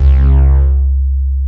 REZMOOG C2-L.wav